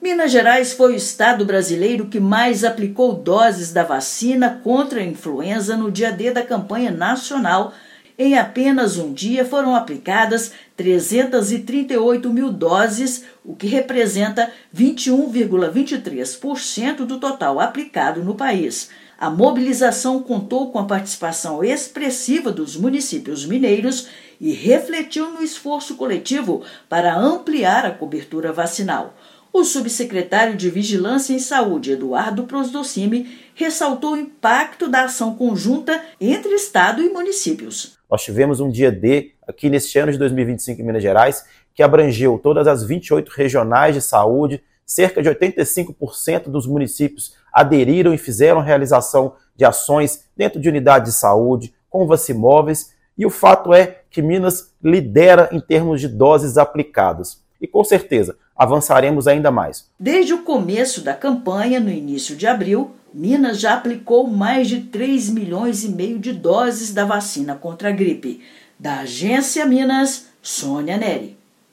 [RÁDIO] Minas Gerais lidera vacinação contra influenza no dia D da campanha nacional
Com mais de 3,5 milhões de doses aplicadas desde abril, estado segue vacinando toda a população acima de seis meses de idade. Ouça matéria de rádio.